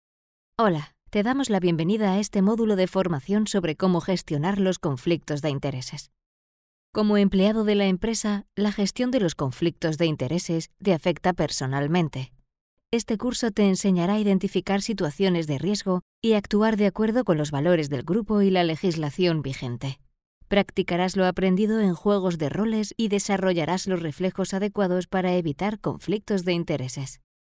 Comercial, Accesible, Versátil, Cálida, Suave
Explicador